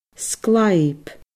sglàib /sgLaib/